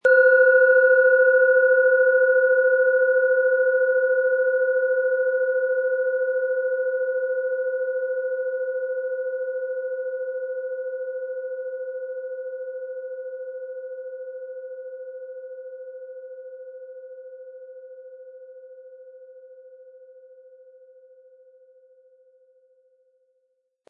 Von Hand getriebene Schale mit dem Planetenton Sonne.
• Mittlerer Ton: Jupiter
Im Sound-Player - Jetzt reinhören hören Sie den Original-Ton dieser Schale. Wir haben versucht den Ton so authentisch wie machbar hörbar zu machen, damit Sie hören können, wie die Klangschale bei Ihnen klingen wird.
Durch die traditionsreiche Fertigung hat die Schale vielmehr diesen kraftvollen Ton und das tiefe, innere Berühren der traditionellen Handarbeit
PlanetentöneSonne & Jupiter
MaterialBronze